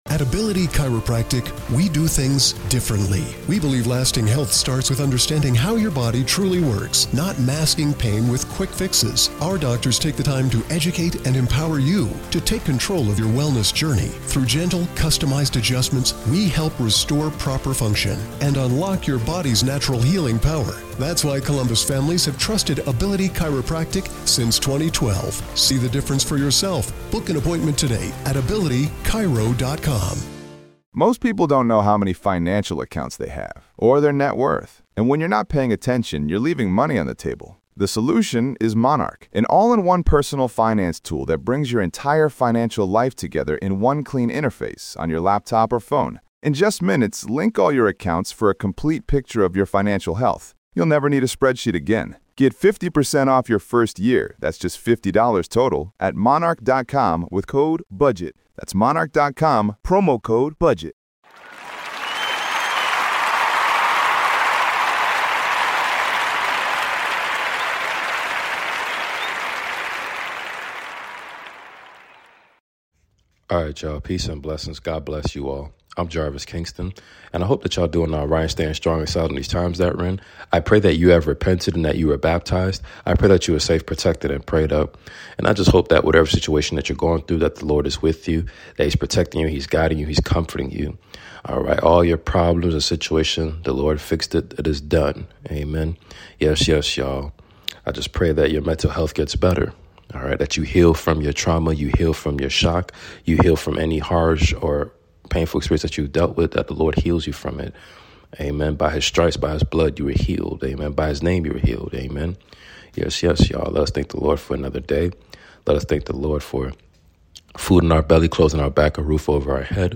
Book of Luke 21-24 reading completion! He went all out for us for our sins and transgressions!